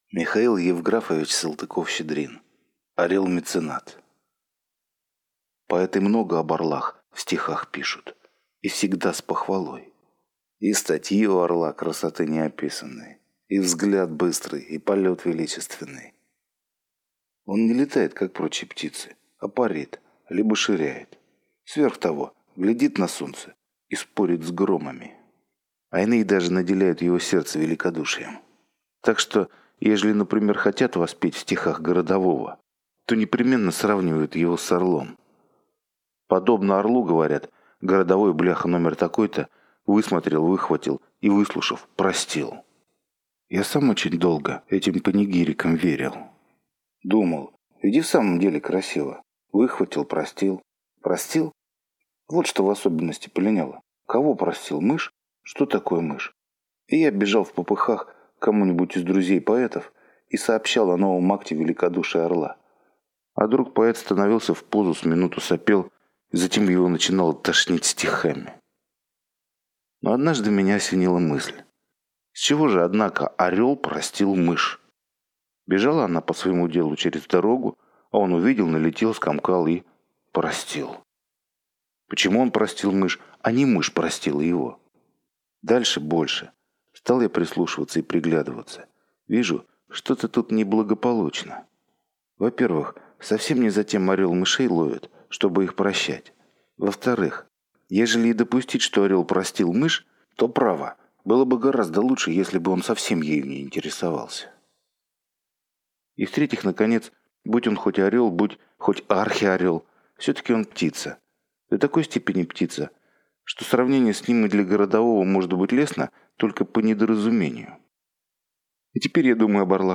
Аудиокнига Орел-меценат | Библиотека аудиокниг
Aудиокнига Орел-меценат Автор Михаил Салтыков-Щедрин Читает аудиокнигу Алгебра Слова.